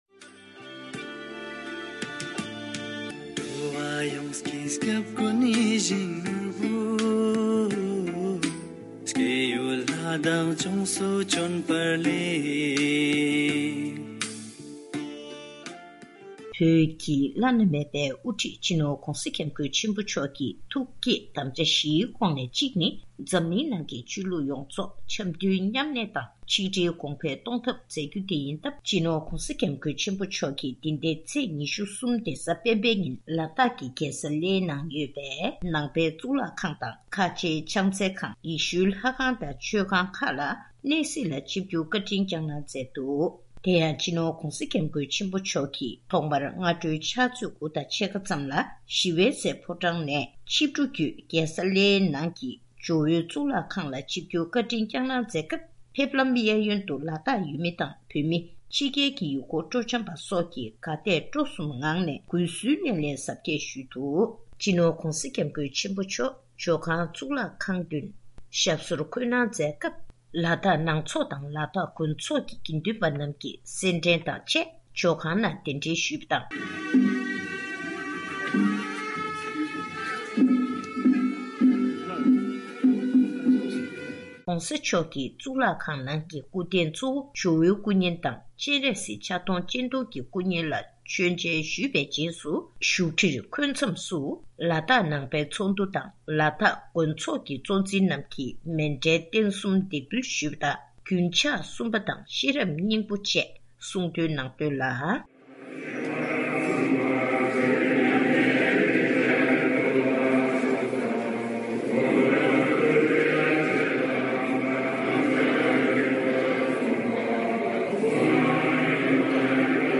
ཕྱོགས་བསྡུས་ཞུས་པའི་གནས་ཚུལ།